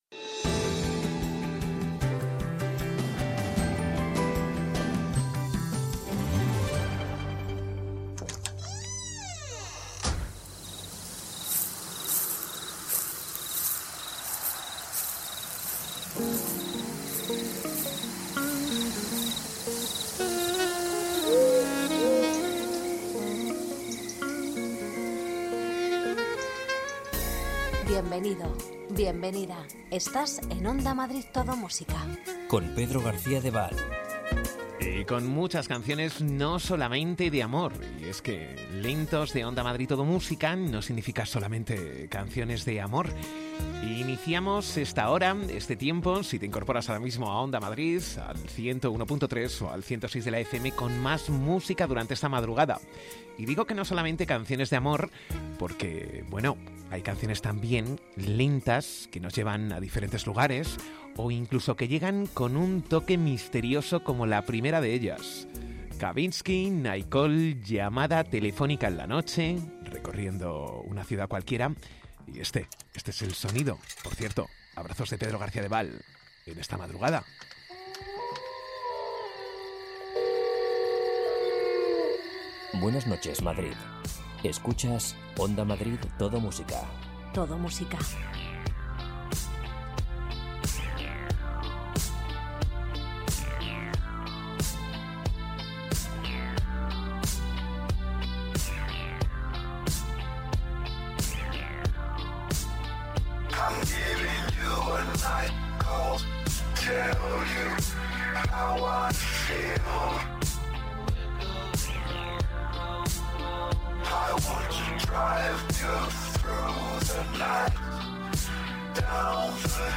Ritmo tranquilo, sosegado, sin prisas...